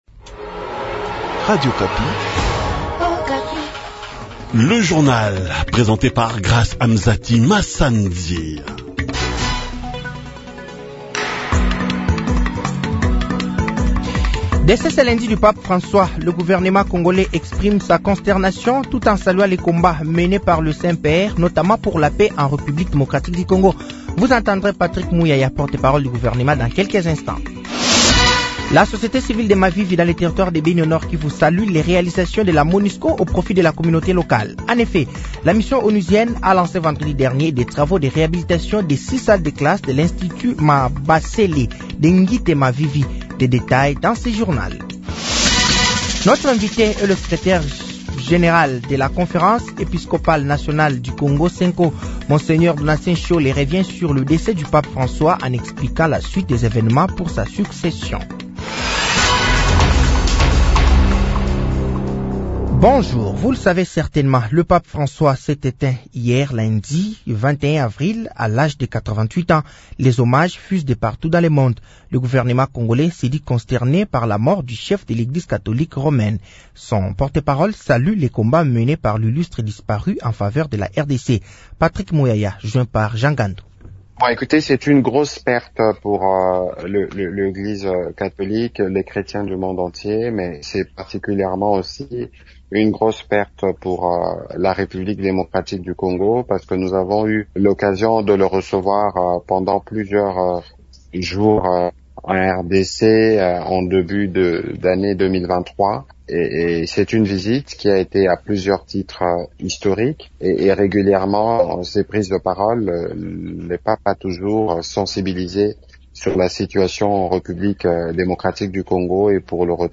Journal français de 08h de ce mardi 22 avril 2025